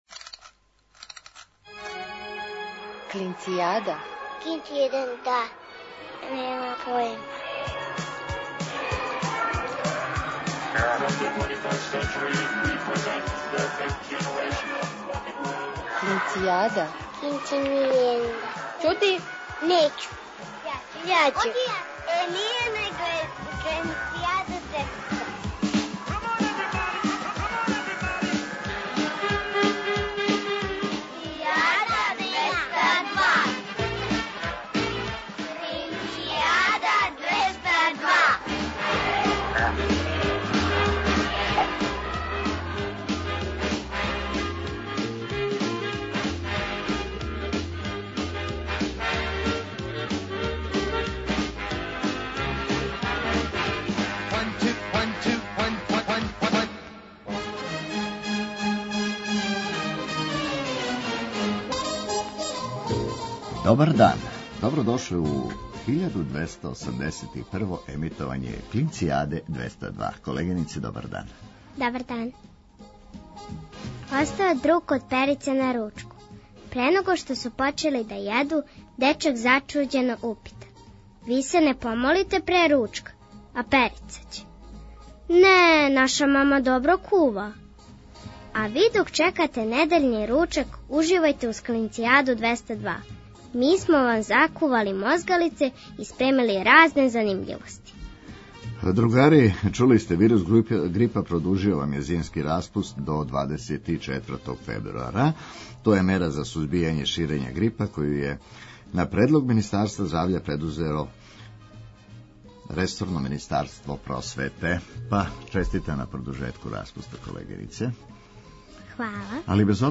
У њему тестирамо ваше познавање домаће и регионалне поп и рок музике, уз певање уживо у програму.